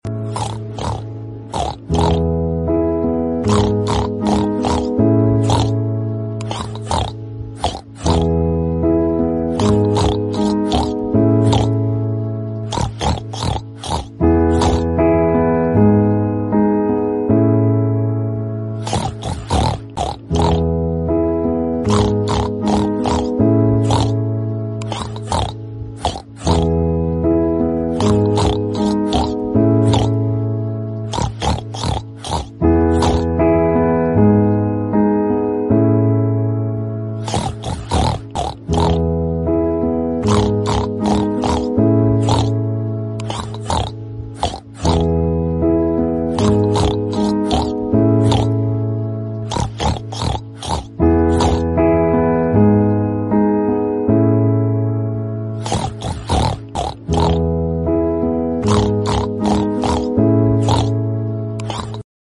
Anak Babi 🐷❤ Sedih Kelaparan Sound Effects Free Download